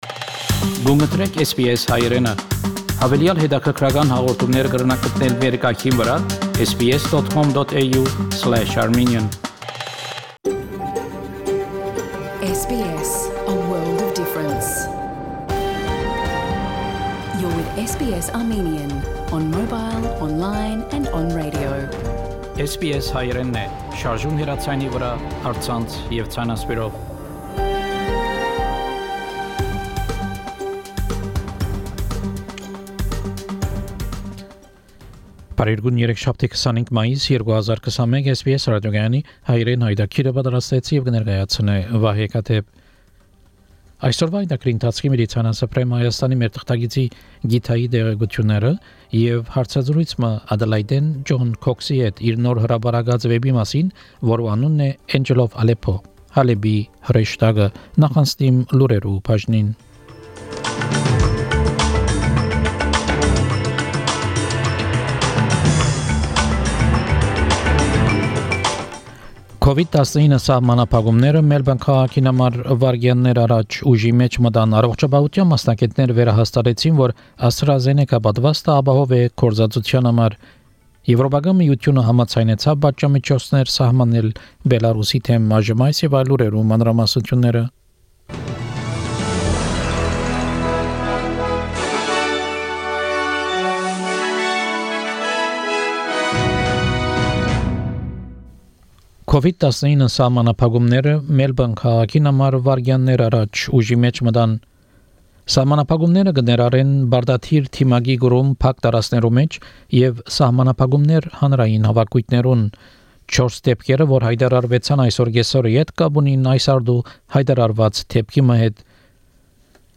SBS Armenian news bulletin – 25 May 2021
SBS Armenian news bulletin from 25 May 2021 program.